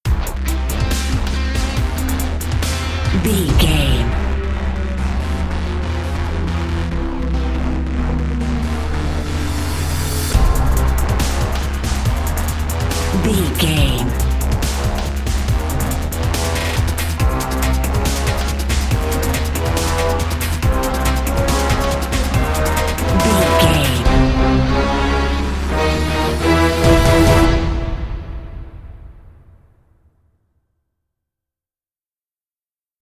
Epic / Action
Aeolian/Minor
strings
drum machine
synthesiser
brass
driving drum beat